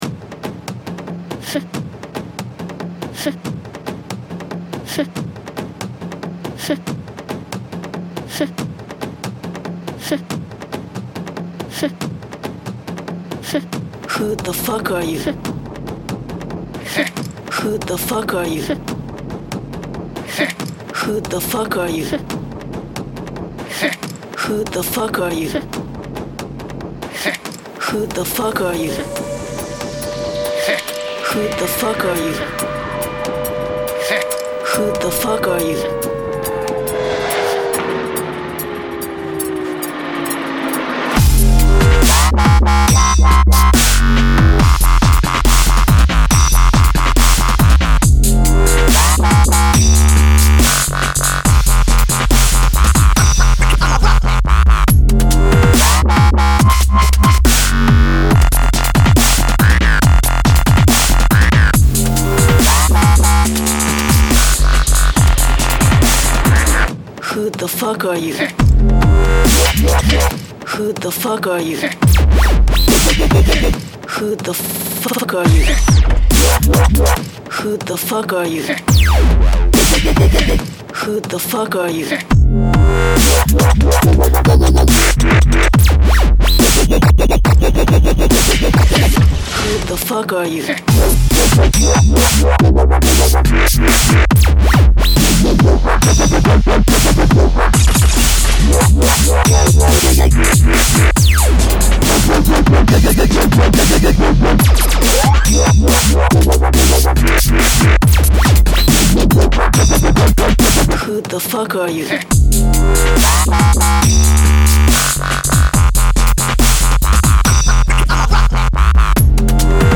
Category: Up Tempo